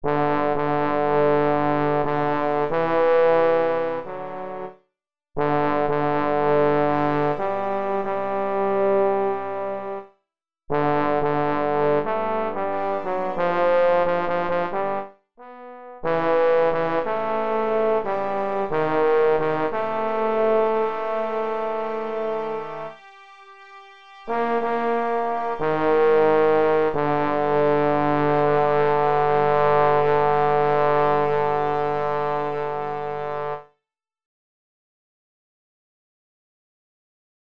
Key written in: G Major
Type: Barbershop